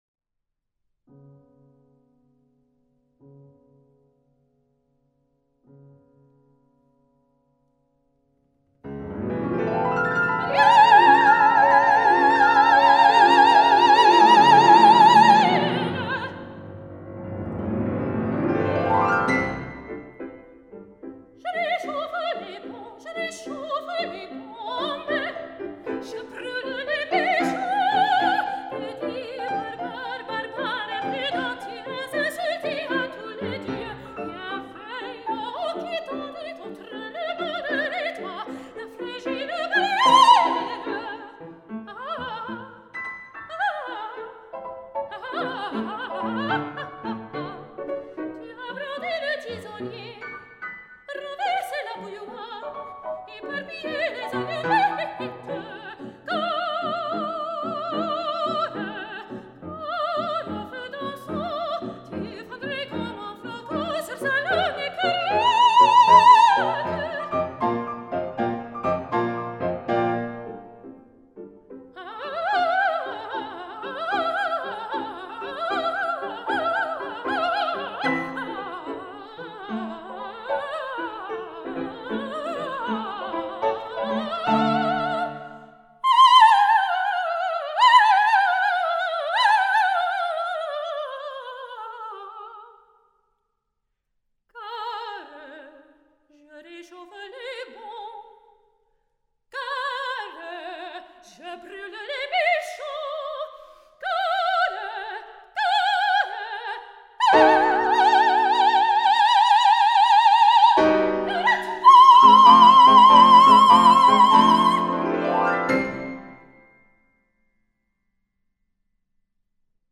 Recorded at the Reitstadel in Neumarkt in der Oberpfalz, Germany, summer 2016.
Soprano
Piano